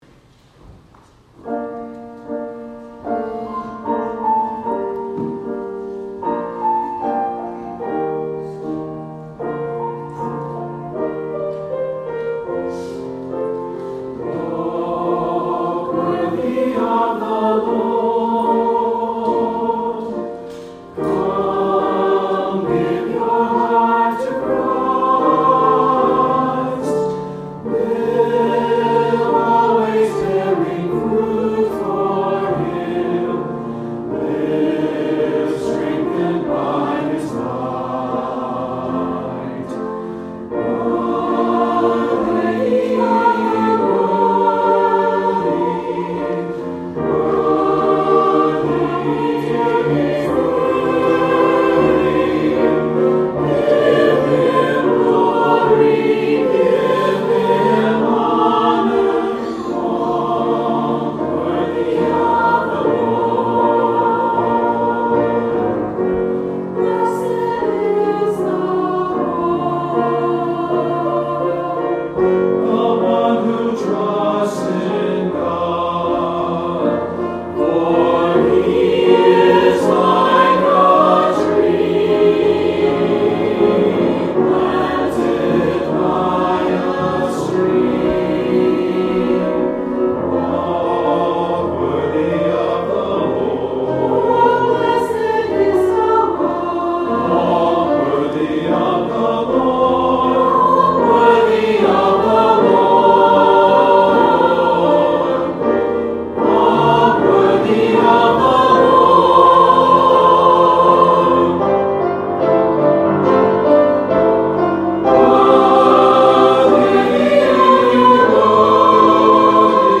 Offertory: Trinity Chancel Choir